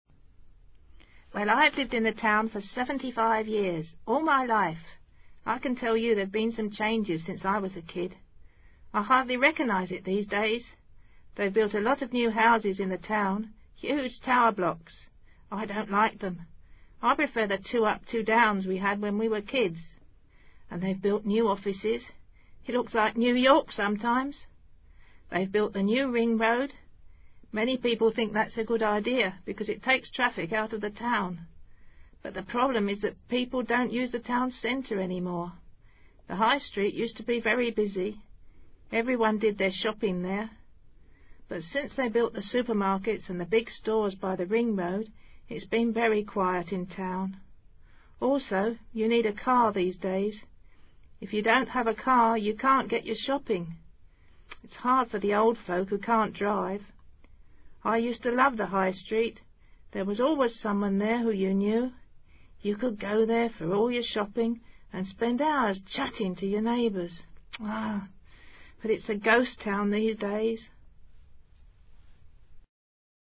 Una mujer mayor comenta sobre los cambios ocurridos en su ciudad a través de los años.